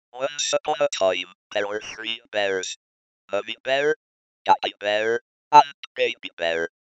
Speech synthesizer, есть семплы?
klatt_example.mp3